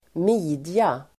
Ladda ner uttalet
Uttal: [²m'i:dja]